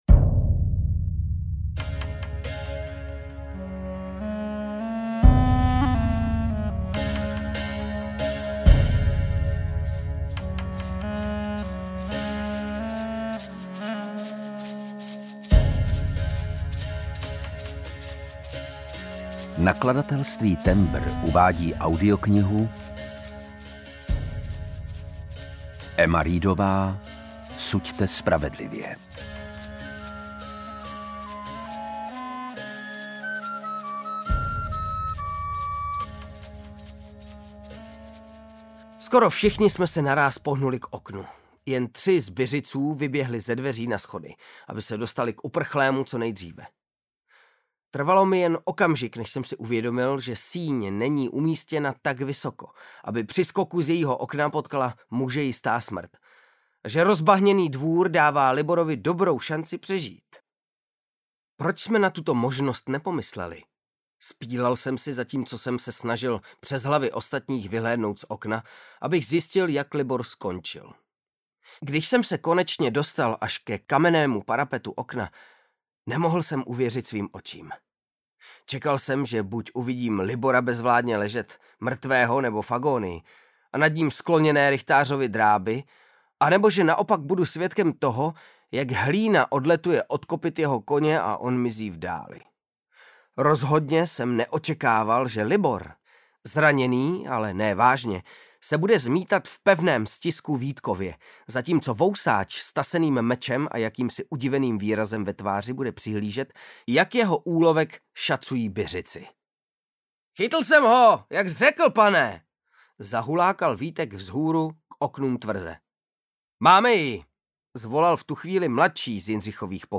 Suďte spravedlivě audiokniha
Ukázka z knihy
• InterpretKryštof Hádek, Lukáš Hlavica, Vasil Fridrich
sudte-spravedlive-audiokniha